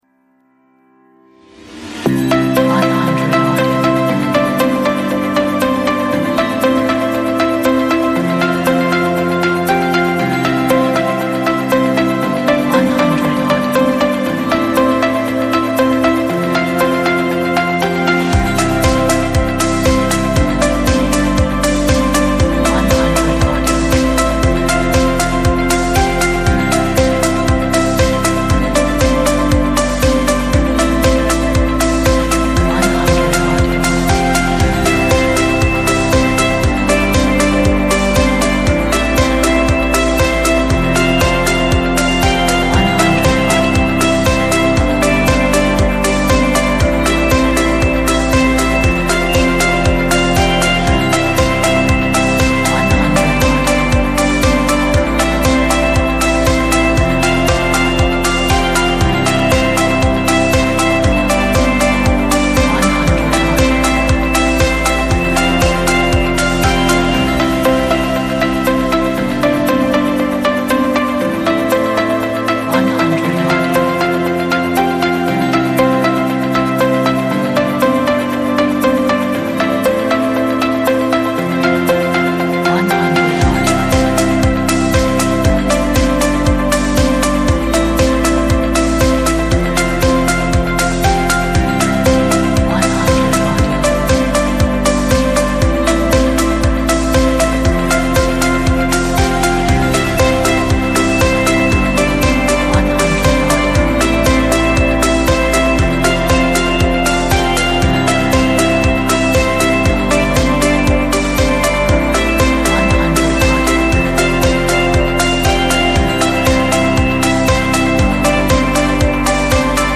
a beautiful pop upbeat inspirational track
这是一首优美 乐观 鼓舞人心的流行音乐。